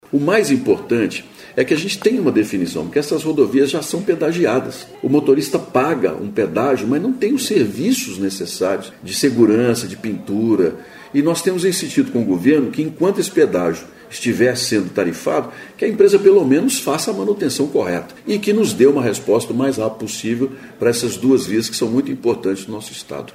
Senador Carlos Viana